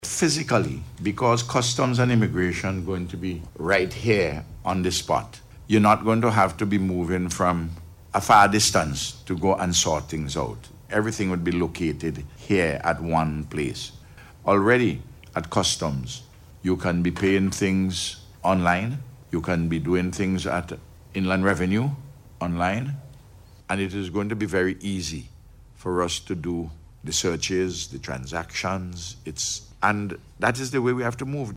The St. Vincent and the Grenadines Port Authority hosted a Media Conference yesterday to discuss the transition to the 750-million dollar Modern Port.